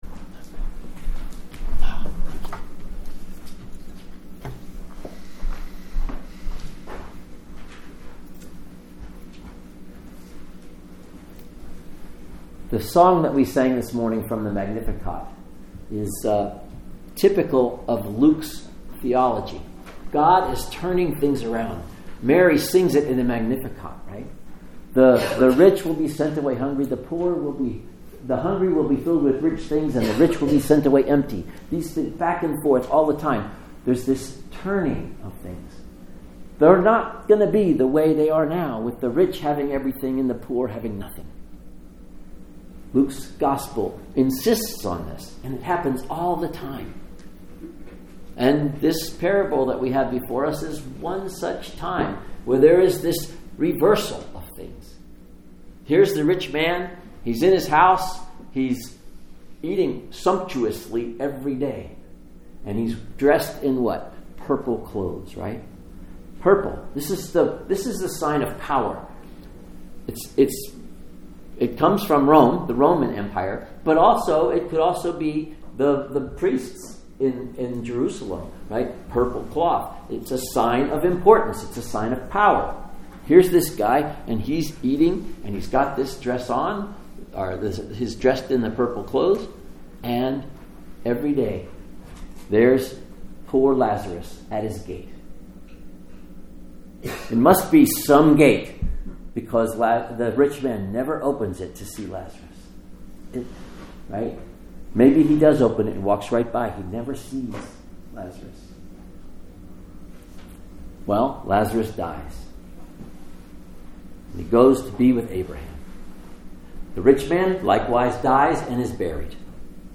Sermons | Lake Chelan Lutheran Church